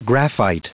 Prononciation du mot graphite en anglais (fichier audio)
Prononciation du mot : graphite